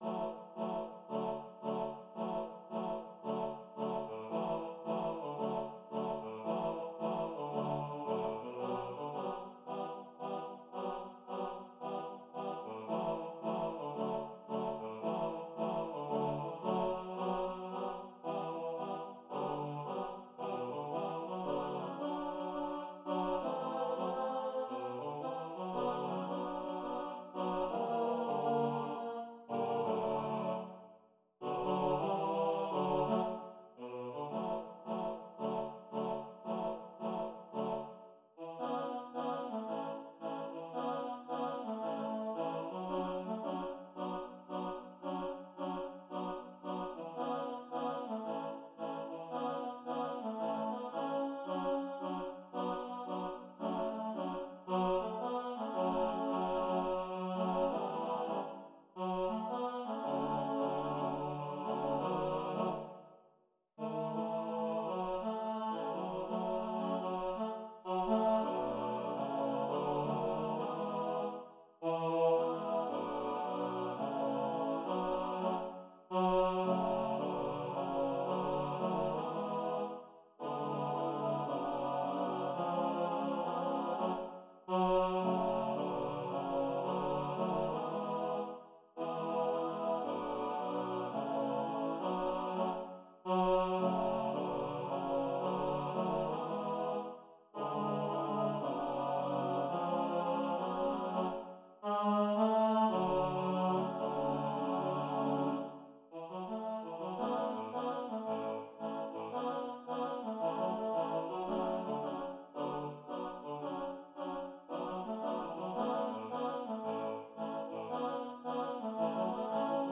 kan synges både a cappella og med akkompagnement